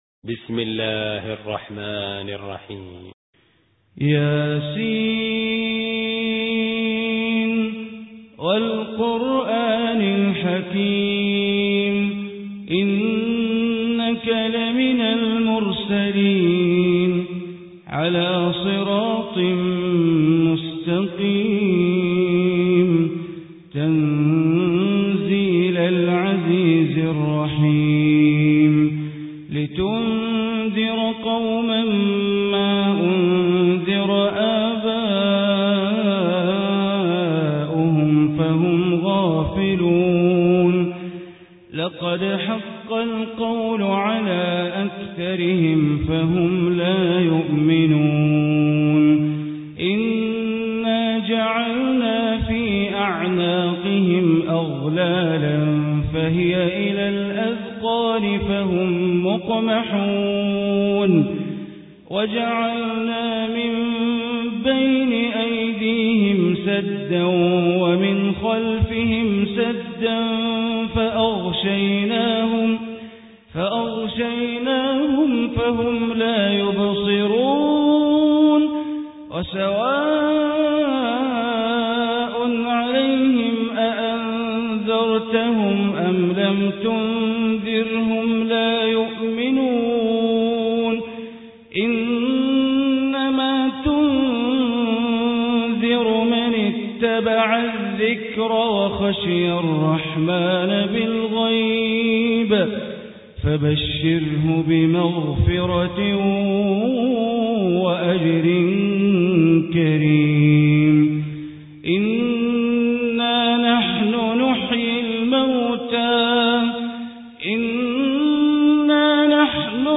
Surah Yaseen Recitation by Sheikh Bandar Baleela
Surah Yaseen is heart of Quran. Listen online mp3 tilawat / recitation in Arabic recited by Sheikh Bandar Baleela.
36-surah-yaseen.mp3